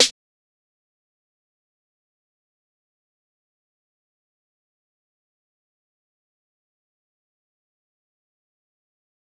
JJSnares (19).wav